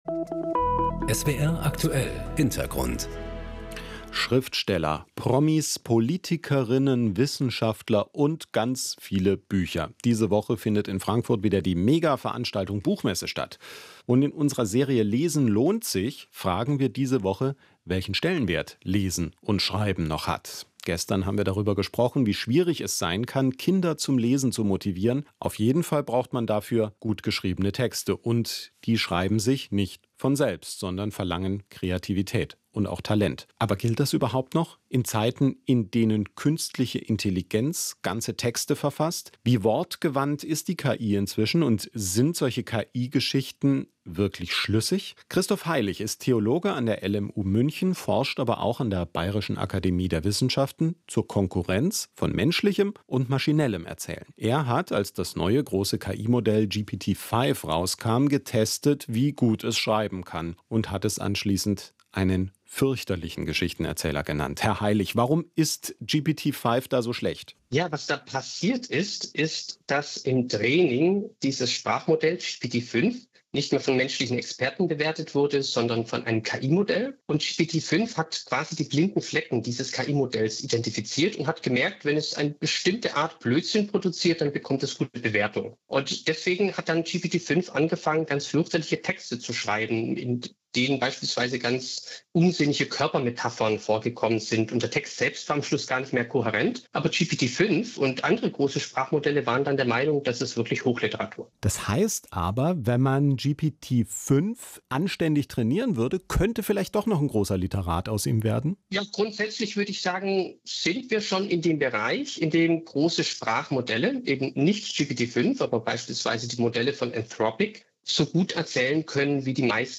• Etwas ausführlicher und im O-Ton kam ich dann im radio3 des rbb zu Wort, nämlich in der Sendung "Unser Leben", eine Sendung über Fragen aus Sozialpolitik, Ethik, Psychologie und Religion.